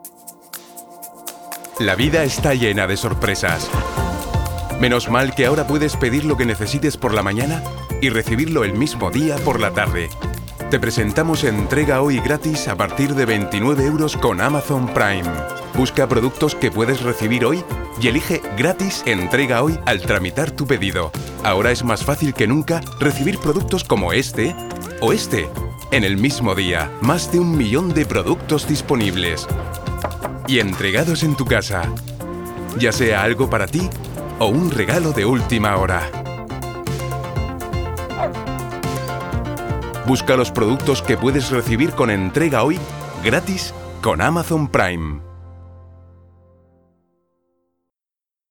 Voice actor for television, radio, film commercial recordings. Dramatic interpretation. Company videos, etc
Sprechprobe: Werbung (Muttersprache):
Voice actor who can be kind, energetic, arrogant, corporate, compassionate, rebellious, caring, evil, gentle, persuasive ......